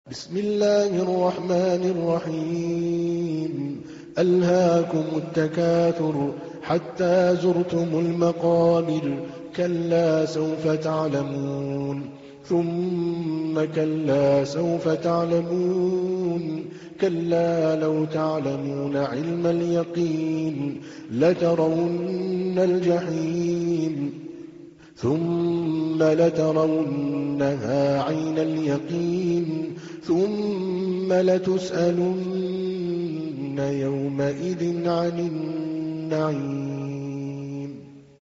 تحميل : 102. سورة التكاثر / القارئ عادل الكلباني / القرآن الكريم / موقع يا حسين